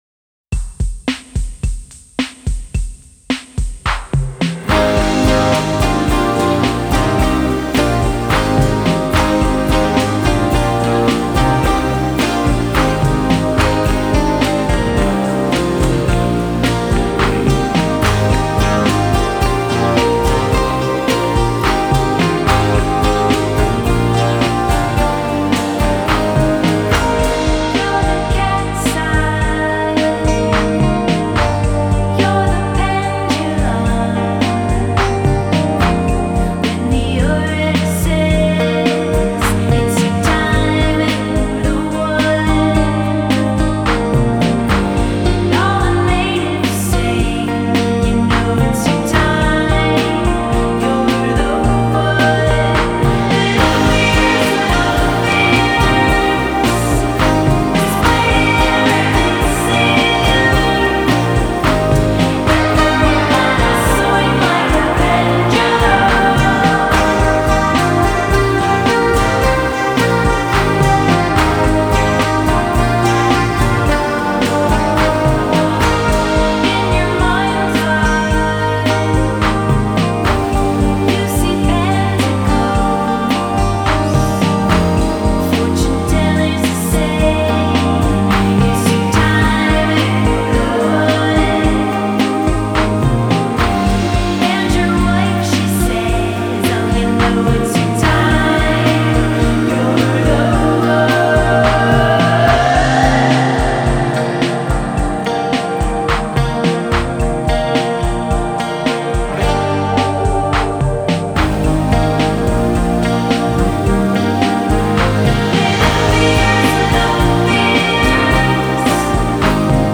guitar
keys and vocals
Instead of walloping you with it, they caress you.